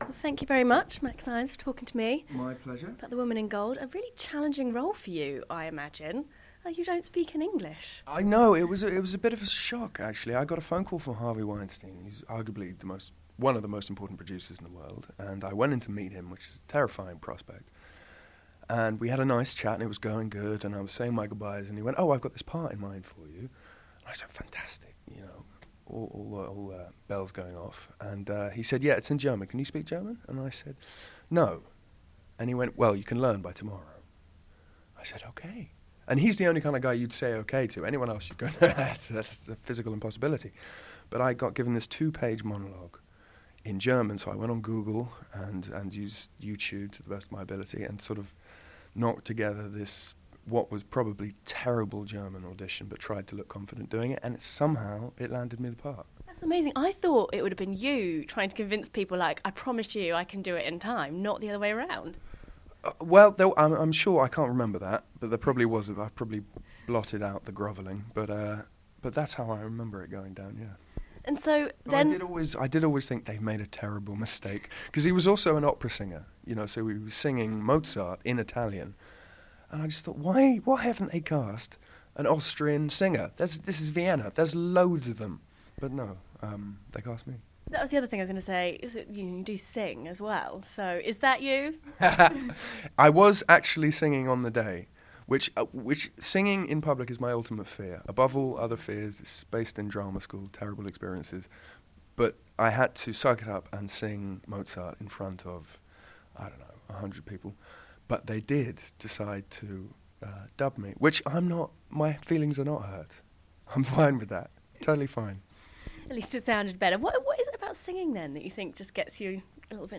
The actor spoke to In:Demand Showbiz about his role in the upcoming movie, learning German in one day, having a famous dad, and more!